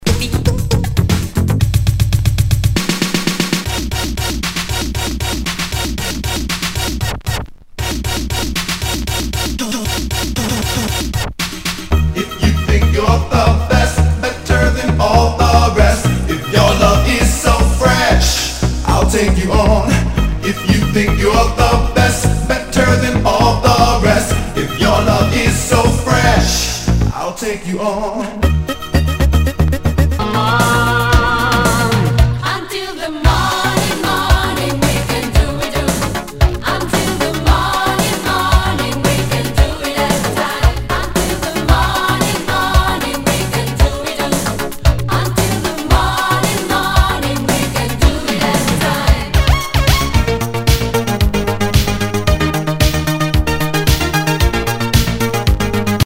類別 Disco
SOUL/FUNK/DISCO
ナイス！シンセ・ポップ・ディスコ・メガミックス！
全体にチリノイズが入ります。